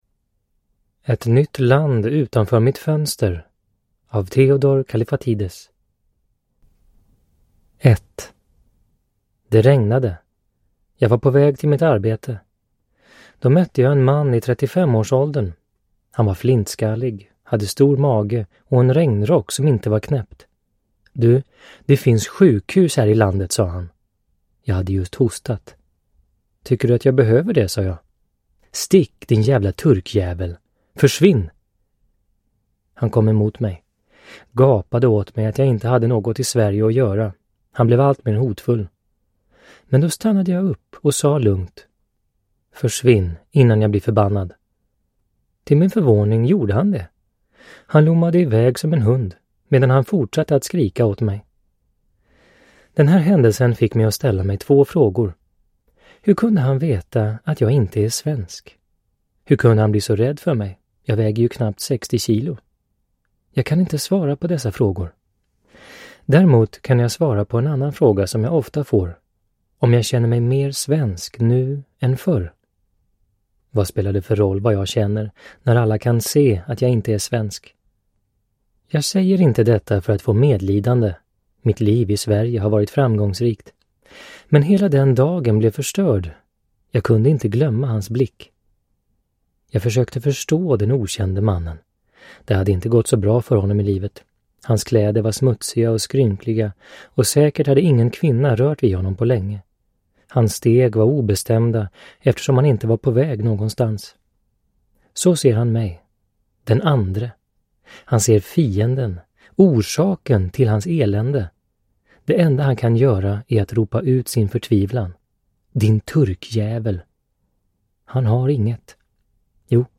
Ett nytt land utanför mitt fönster (lättläst) (ljudbok) av Theodor Kallifatides